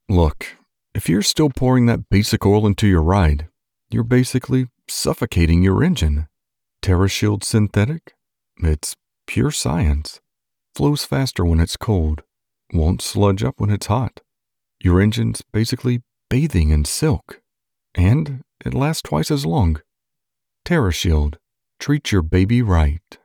male voiceover artist with a rich, deep, and exceptionally smooth vocal tone.
Conversational
General American, Southern
Voice Demo - Synthetic Oil - Conversational.mp3